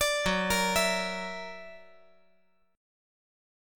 F#+7 Chord
Listen to F#+7 strummed